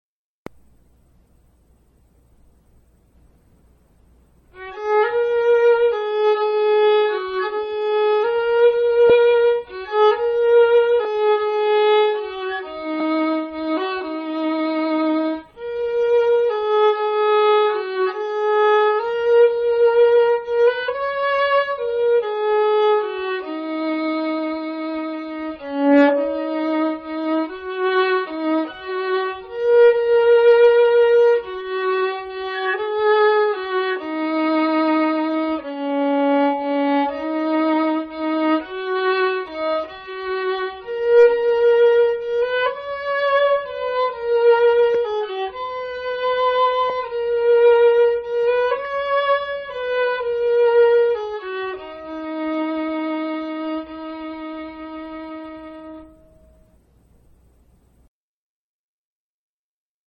waltz.mp3